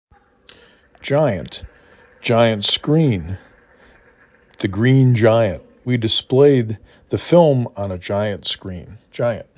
'gi ant
j I ə n t
' = primary stress ə = schwa